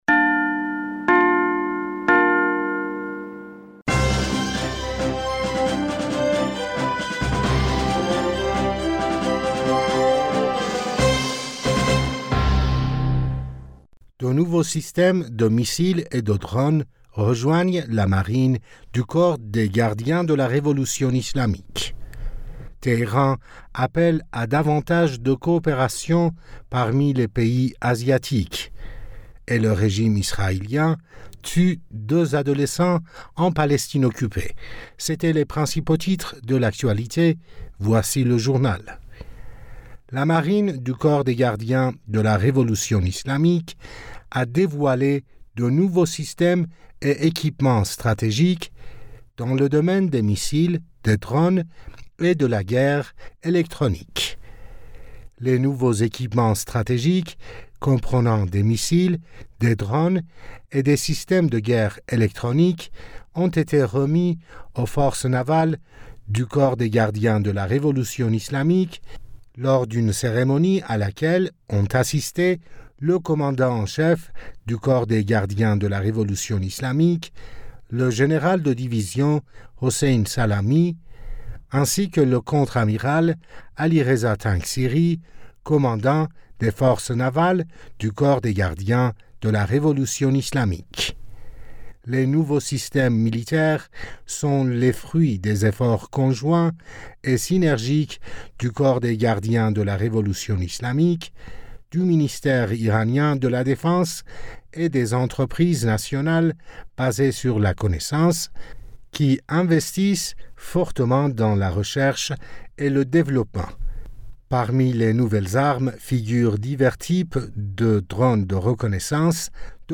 Bulletin d'information du 05 Aout 2023